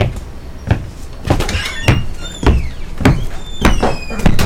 较慢的洗牌草好
描述：脚所产生的声音在后院的草地上相对缓慢地在草地上移动
Tag: 脚步 洗牌 洗牌 户外